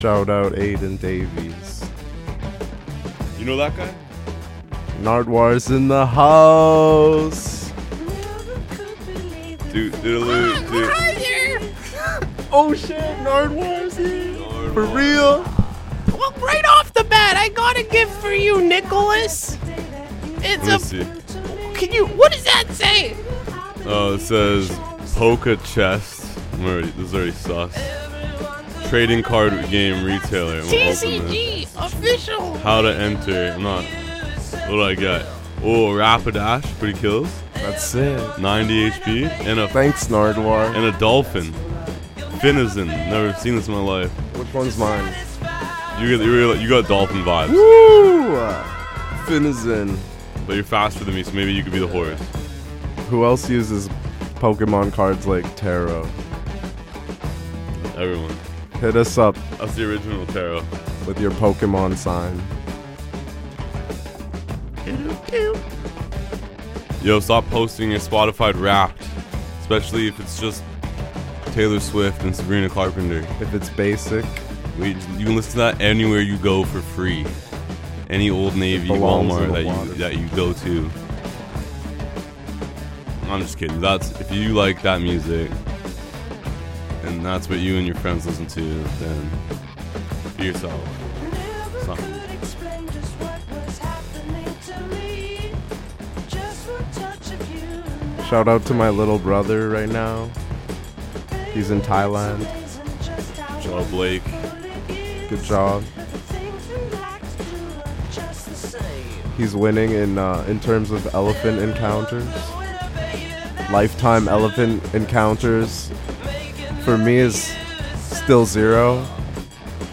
Musique du Vietnam et de la diaspora vietnamienne